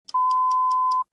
alert.aac